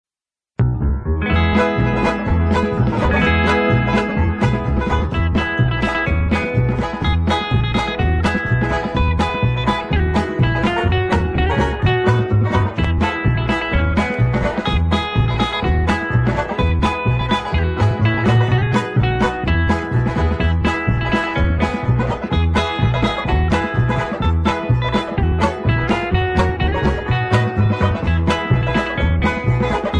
Singing Call
Inst